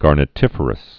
(gärnĭ-tĭfər-əs)